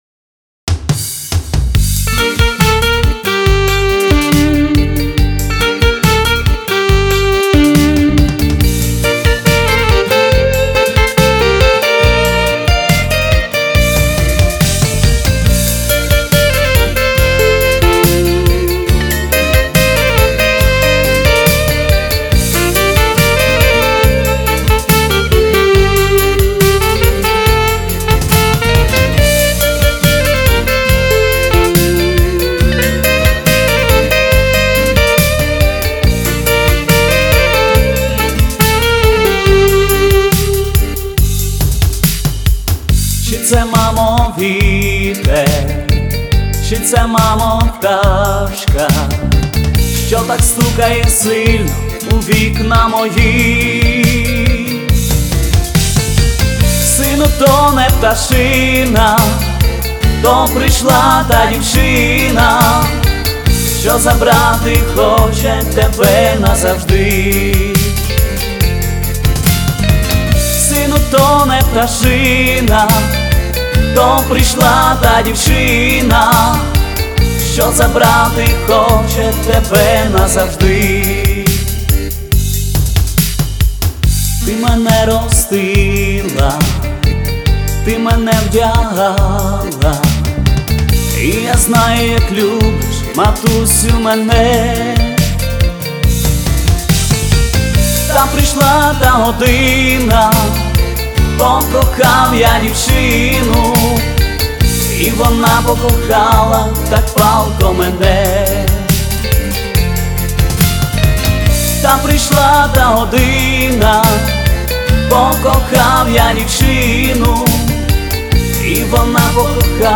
Всі мінусовки жанру Dance
Плюсовий запис